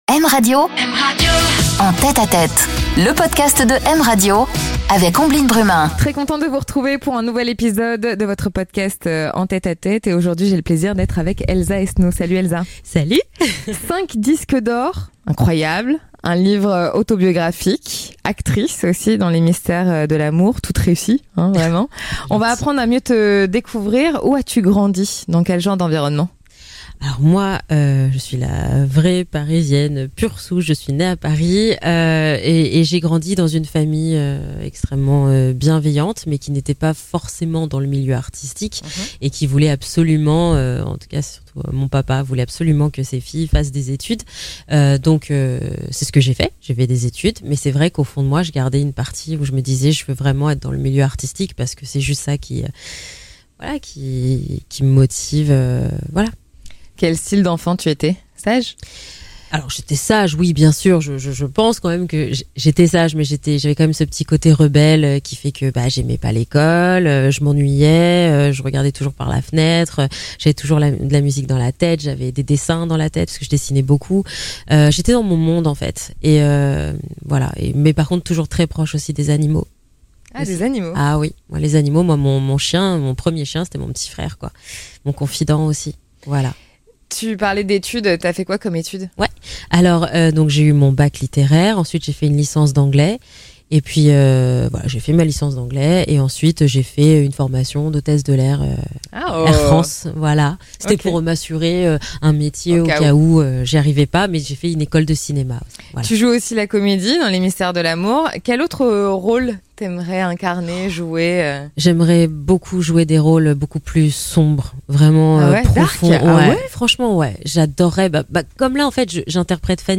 Une interview en confidence, dans l'intimité des artistes L'actrice et chanteuse est de retour avec une Edition Collector de son album de Noël